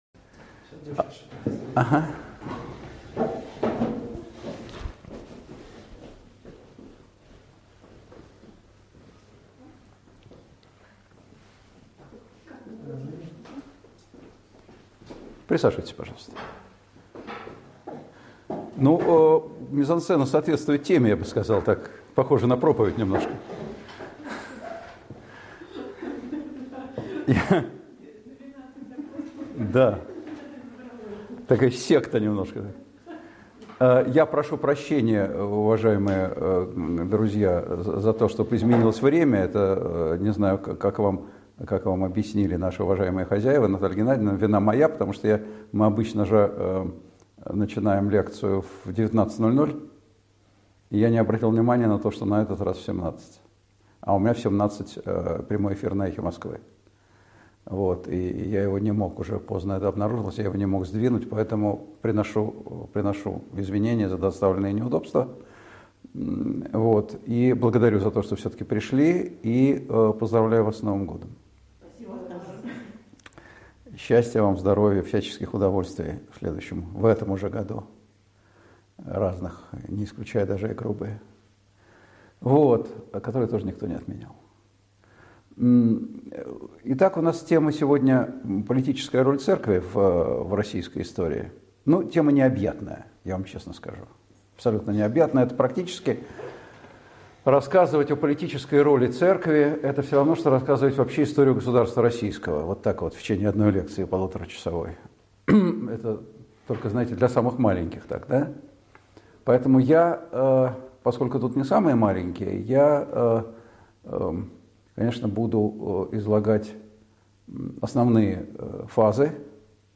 Аудиокнига Религия как значимый игрок в истории России | Библиотека аудиокниг
Aудиокнига Религия как значимый игрок в истории России Автор Николай Сванидзе Читает аудиокнигу Николай Сванидзе.